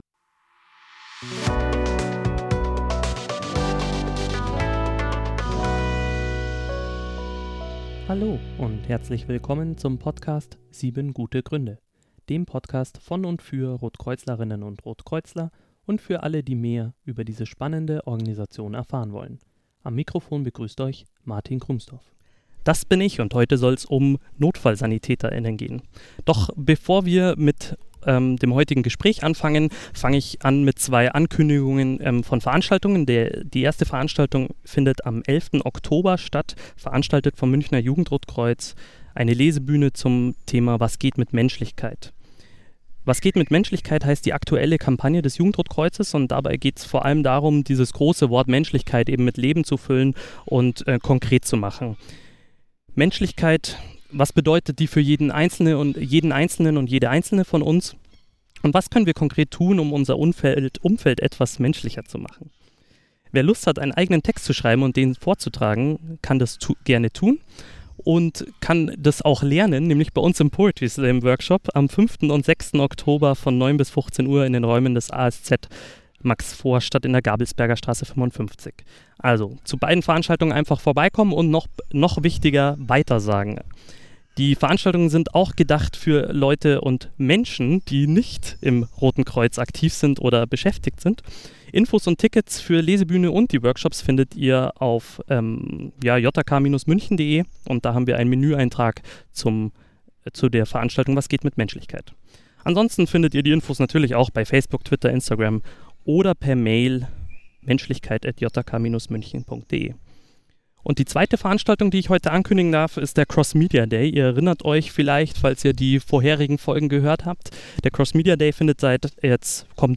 In der ersten Outdoor-Folge